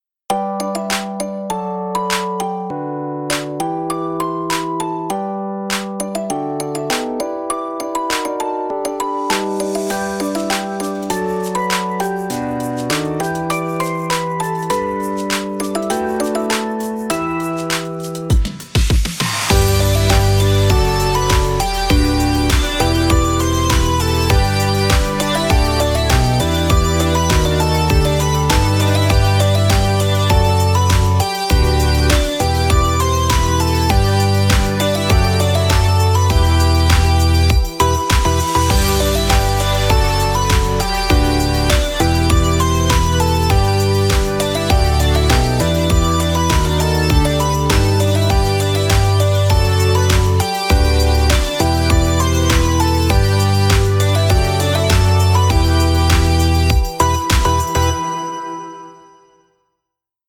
ほのぼの/明るい/かわいい/日常/ゆったり